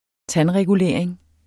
Udtale [ ˈtan- ]